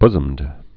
(bzəmd, bzəmd)